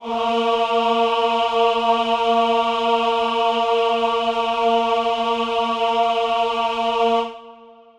Choir Piano (Wav)
A#3.wav